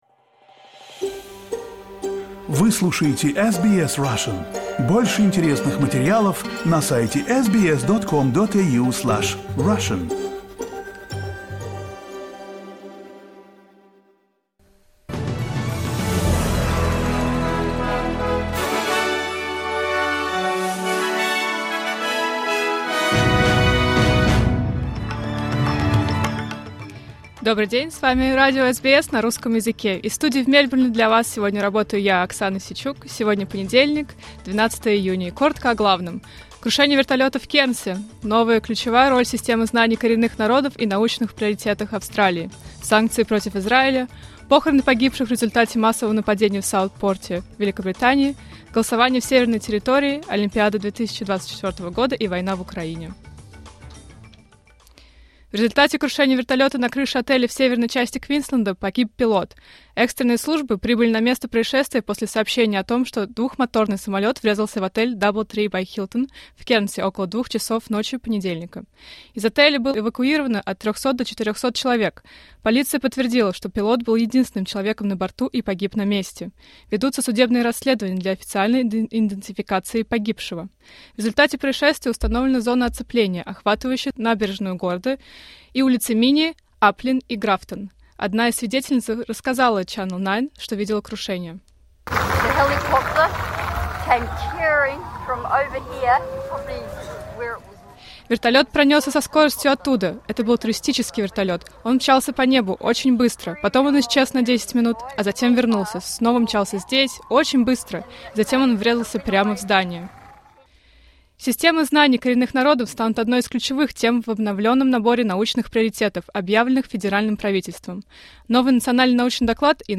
Новости SBS на русском языке — 12.08.2024